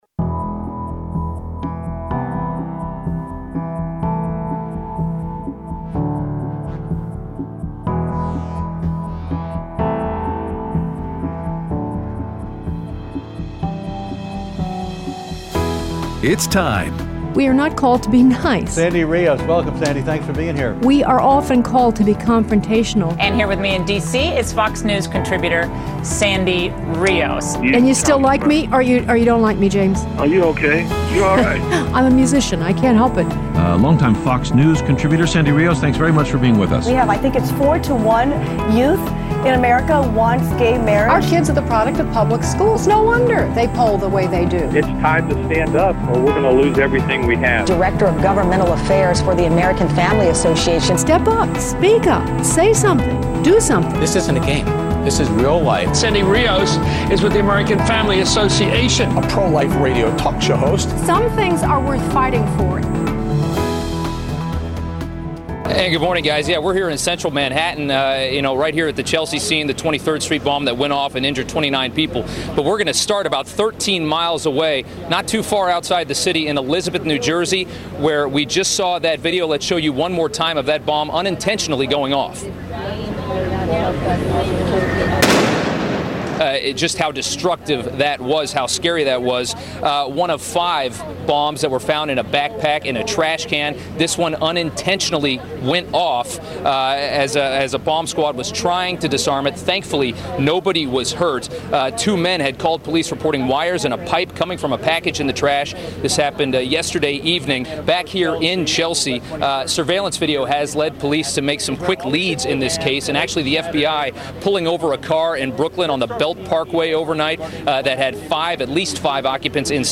Aired Monday 9/19/16 on AFR Talk 7:05AM - 8:00AM CST